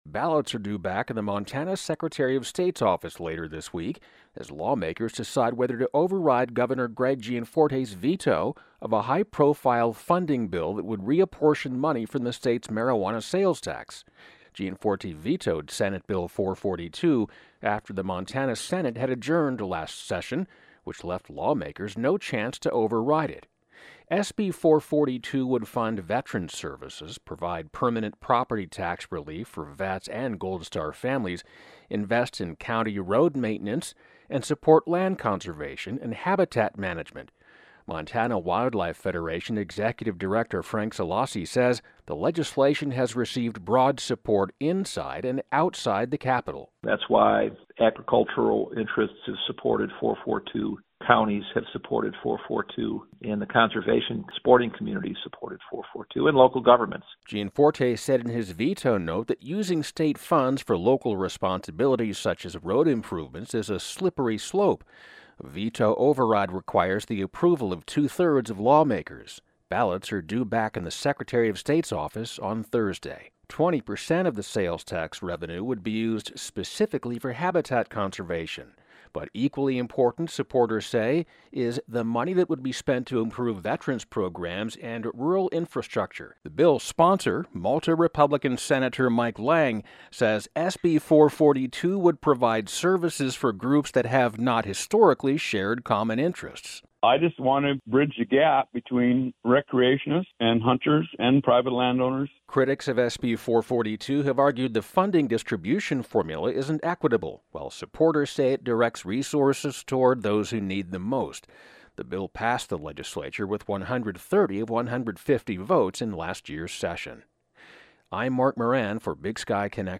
The bill's sponsor, state Sen. Mike Lang - R-Malta - said S.B. 442 would provide services for groups that have not historically shared common interests.